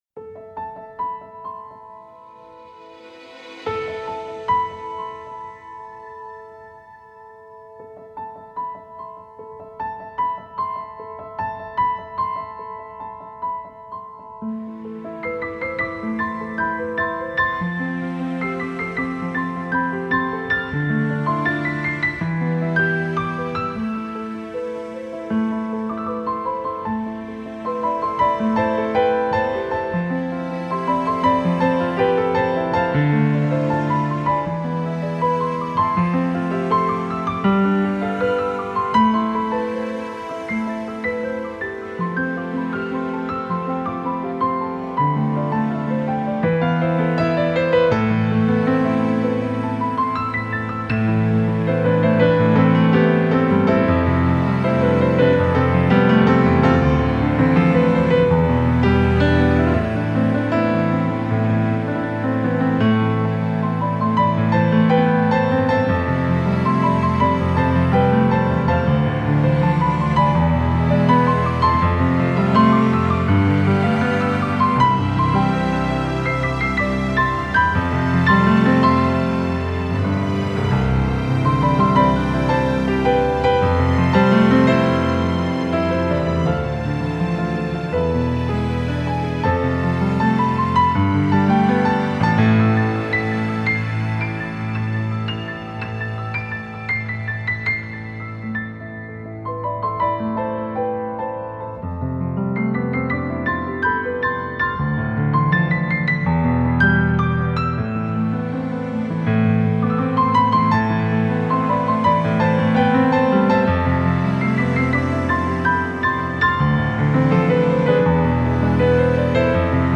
歌曲风格：钢琴演奏 (Piano) / 轻音乐 (Easy Listening) / 纯音乐 (Pure Music)